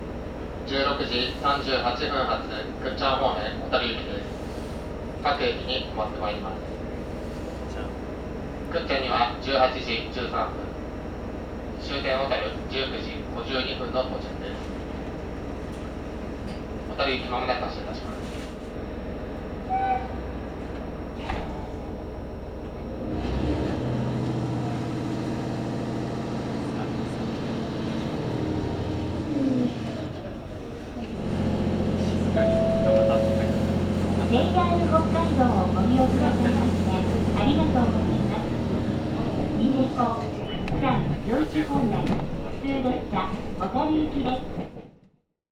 函館本線の音の旅｜長万部駅発車アナウンスと走行音【H100系普通列車・小樽行き】
北海道・函館本線の長万部駅を発車する普通列車小樽行きの音風景。H100系1両編成の発車アナウンスと静かな走行音を収録した音鉄向けショート版。ローカル線の空気感をそのままお届けします。